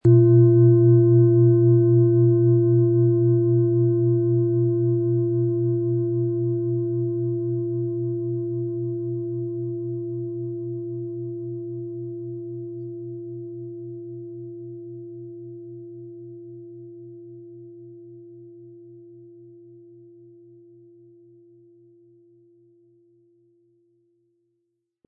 Planetenton 1
Unter dem Artikel-Bild finden Sie den Original-Klang dieser Schale im Audio-Player - Jetzt reinhören.
Vielleicht ist sie nicht so perfekt, aber dafür haben sie den einzigartigen Klang und das außerordentliche, bewegende Schwingen der traditionsreichen Handarbeit.
MaterialBronze